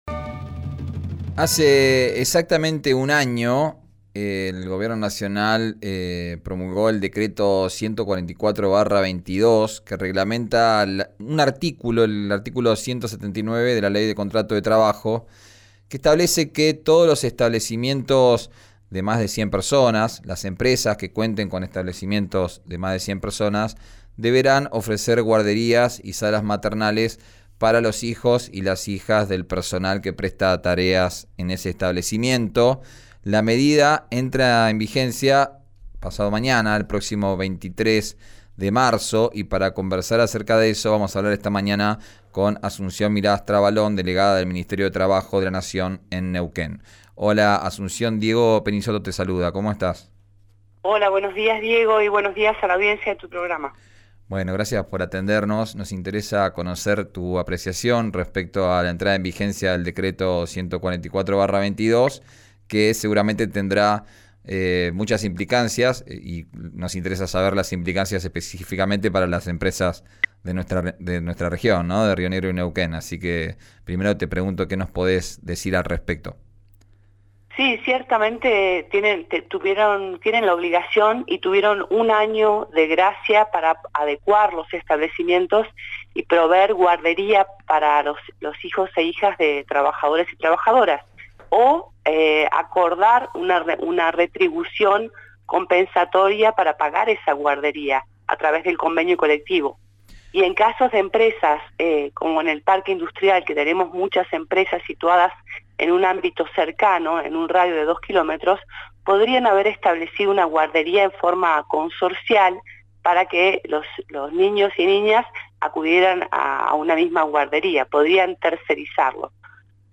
La delegada de Nación en Neuquén por el ministerio de Trabajo, Asunción Miras Trabalón, dialogó con «Arranquemos» por RIO NEGRO RADIO y contó cuales son las implicancias y condiciones de la norma que es de orden publico y que no puede dejarse de lado por decisión de las partes ni acuerdo de trabajadores.